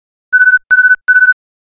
call_ring.mp3